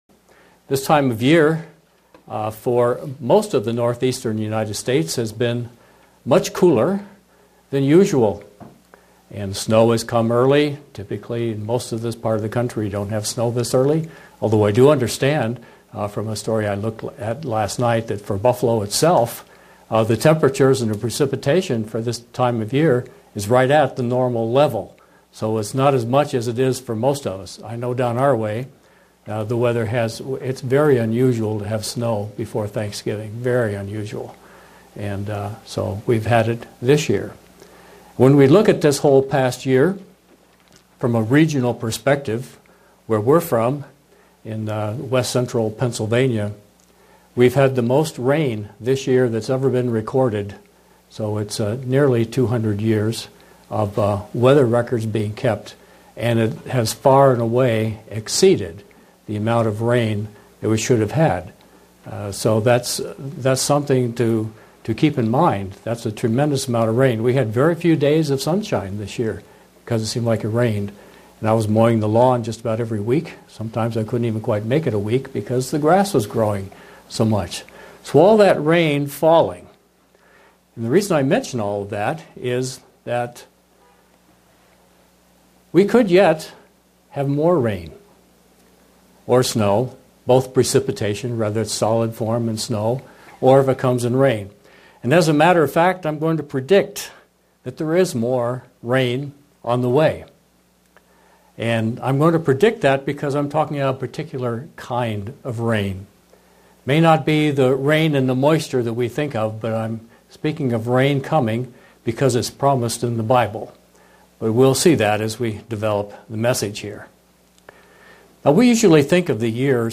Given in Buffalo, NY
Print Two Kinds of Spiritual Harvests That God Expects From Us. sermon Studying the bible?